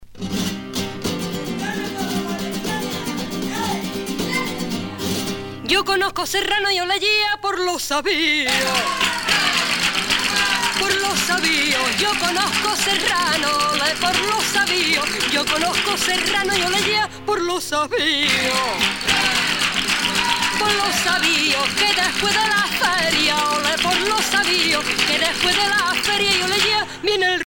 danse : sevillana
Pièce musicale éditée